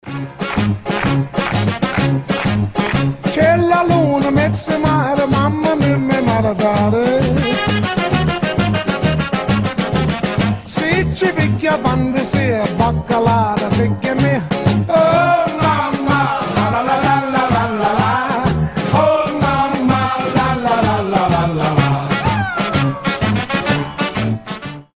• Качество: 320, Stereo
итальянские
блюз